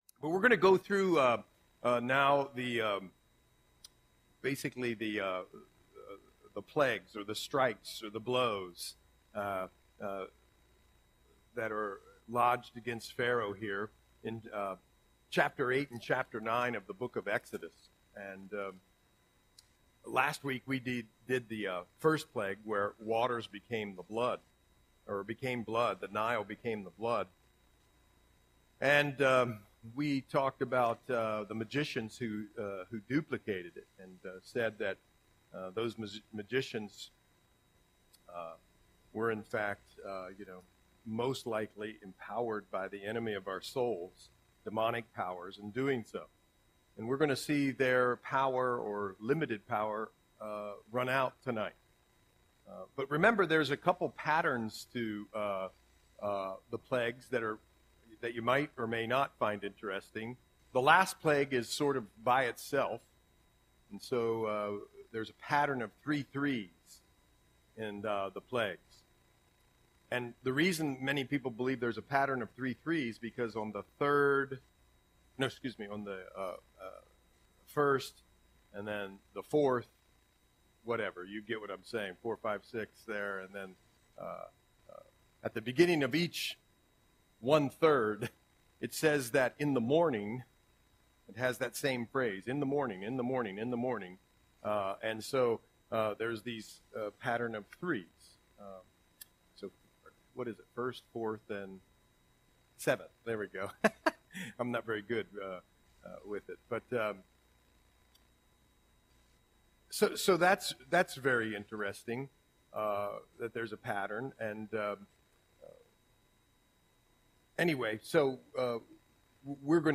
Audio Sermon - December 11, 2024